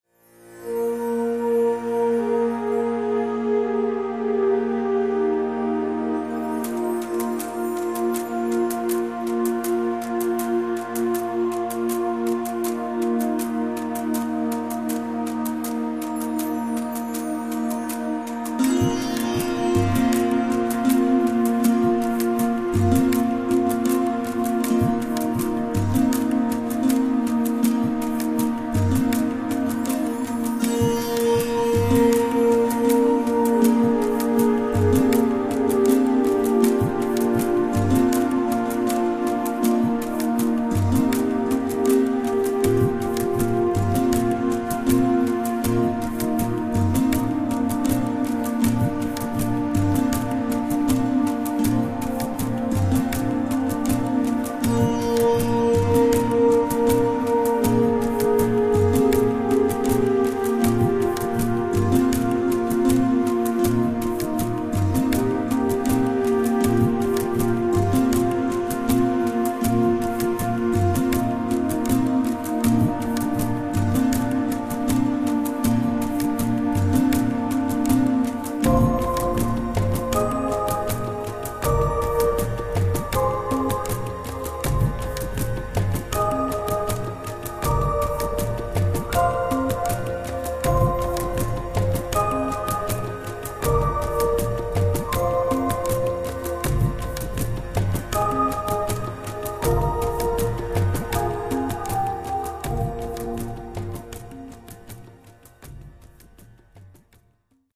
Hudba pro masáže a terapii